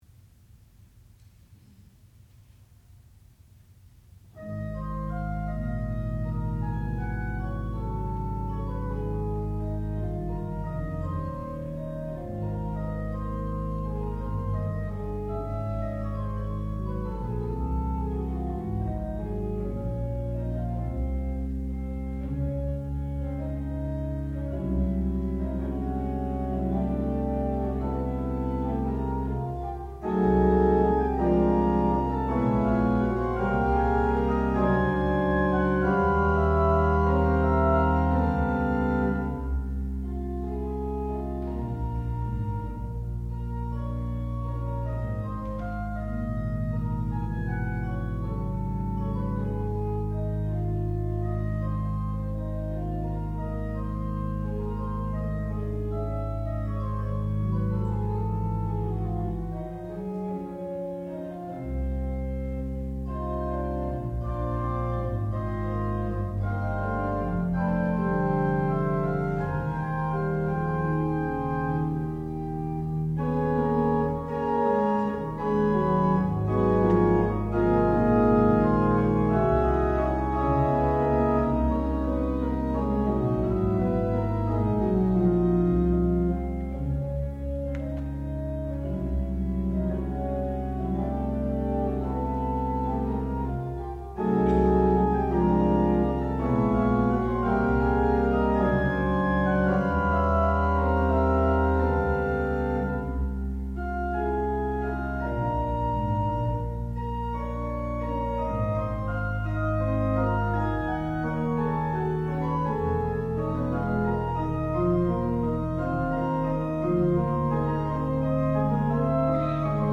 classical music
organ
Master's Recital